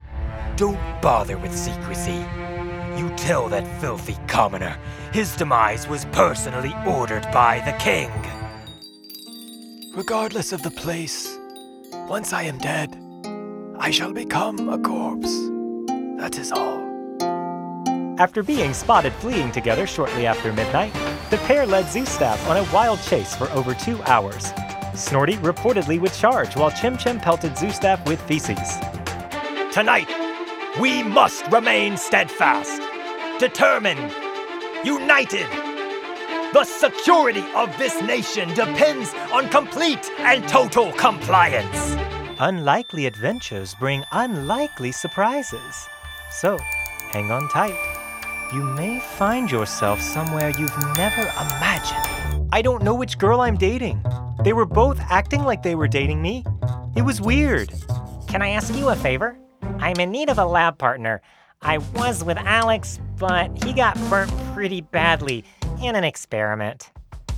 Voiceover Demos
Character Demo